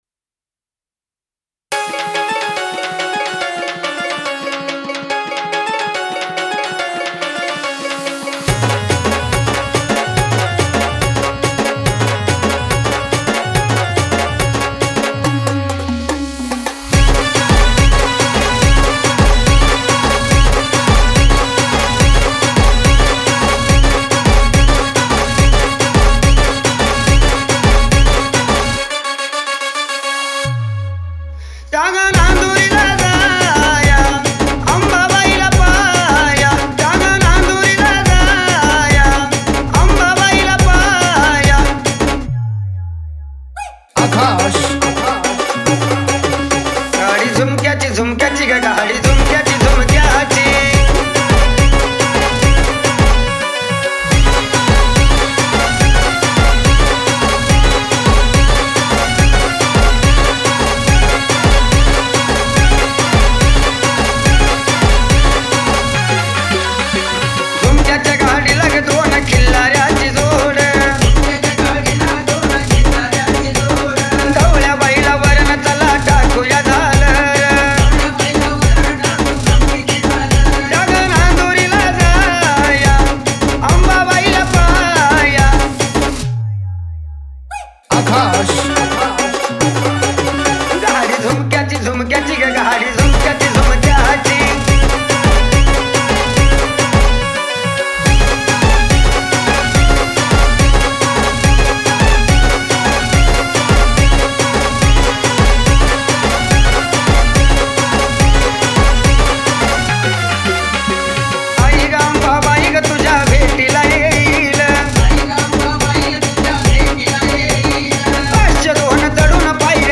Category : Navratri Dj Remix Song